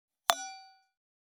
金属製のワインカップ,ステンレスタンブラー,シャンパングラス,ウィスキーグラス,ヴィンテージ,
効果音厨房/台所/レストラン/kitchen食器